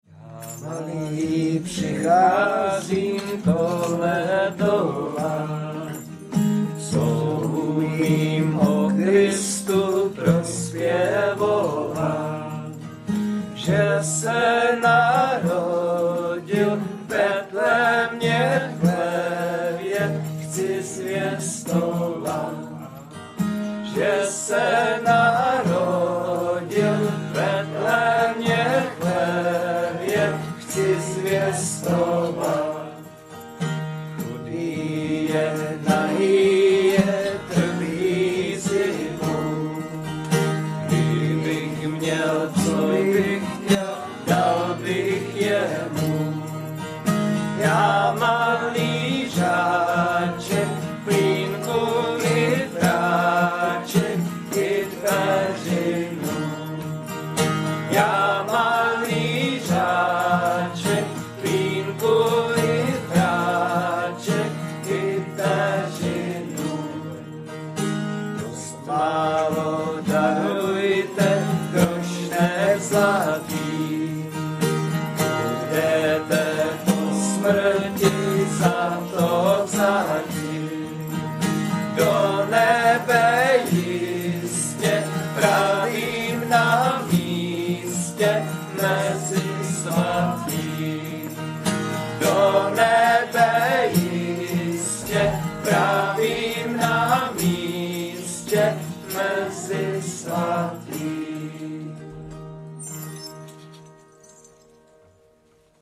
nahrávka ze zpívání